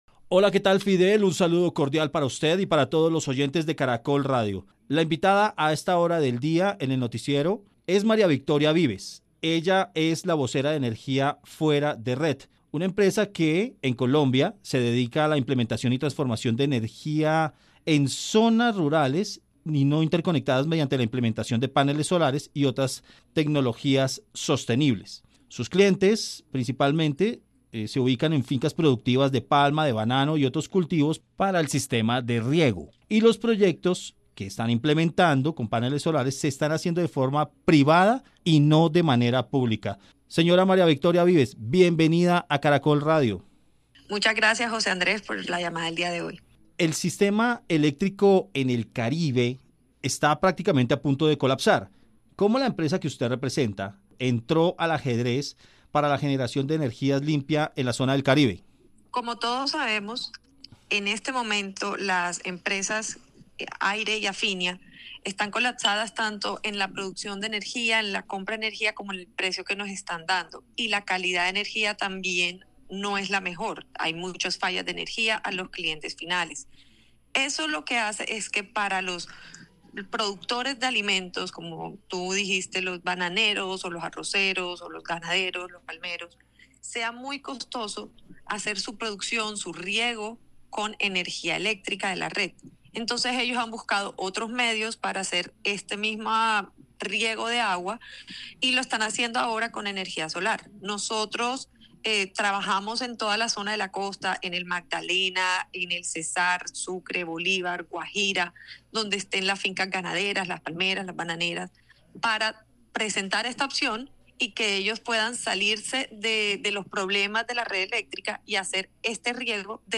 Especialmente el sector finquero está implementando la instalación de paneles solares para los sistemas de riego y así mitigar la crisis de energía en esa zona del país. Entrevista